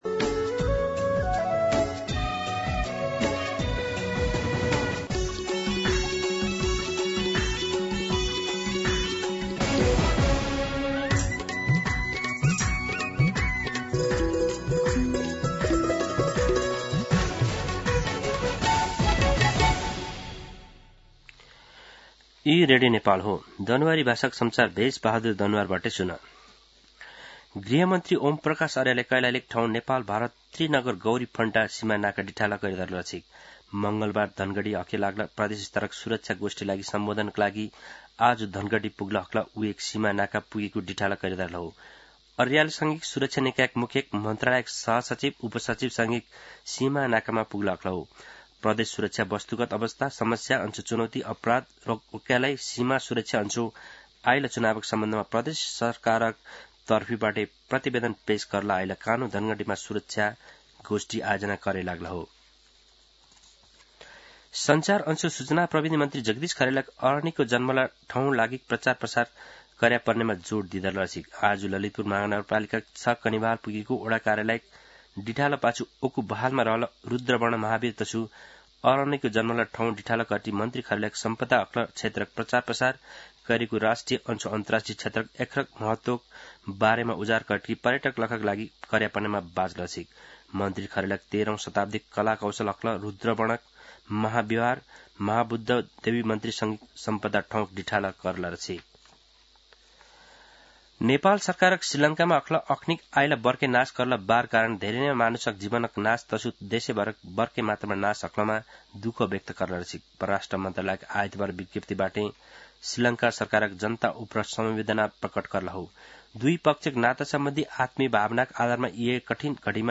दनुवार भाषामा समाचार : १५ मंसिर , २०८२
Danuwar-News-8-15.mp3